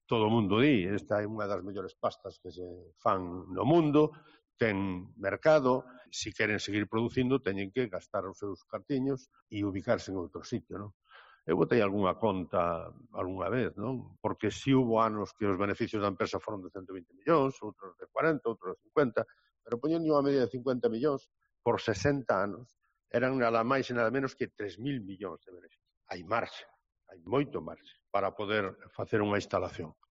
Declaraciones del alcalde de Pontevedra sobre el futuro de ENCE en la ría